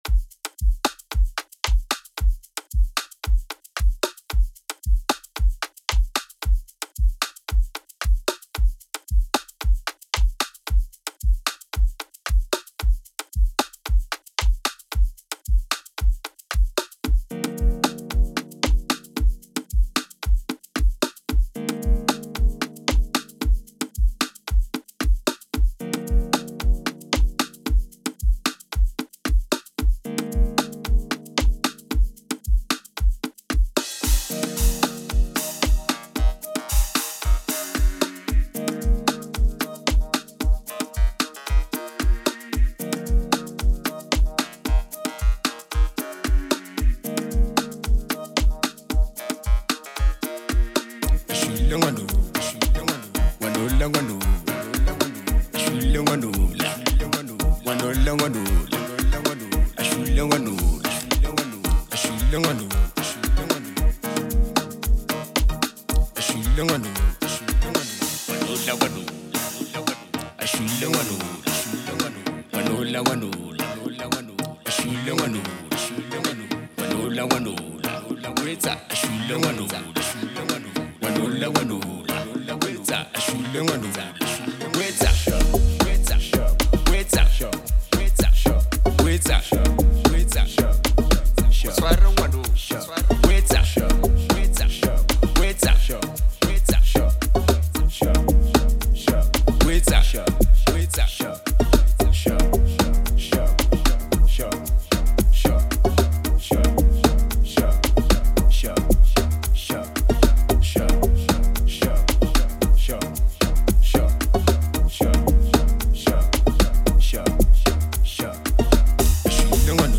house song/Qgom